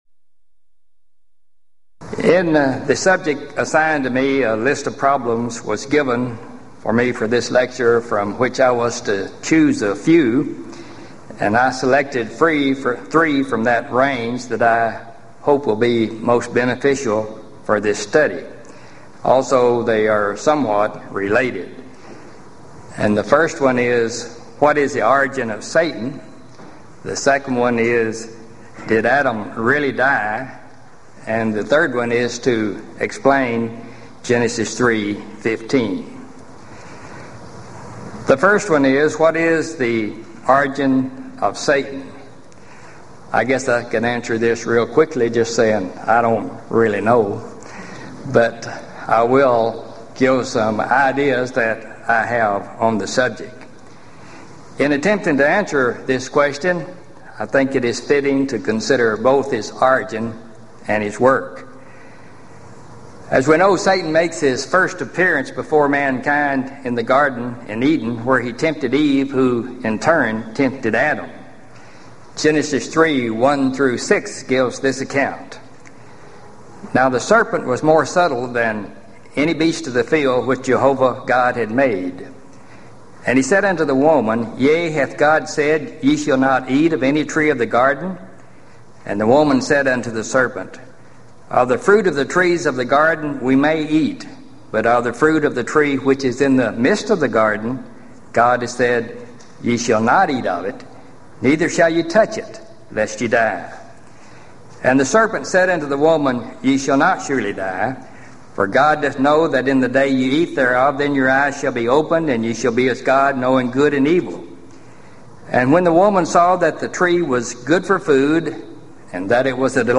Event: 1995 Gulf Coast Lectures Theme/Title: Answering Alleged Contradictions & Problems In The Old Testament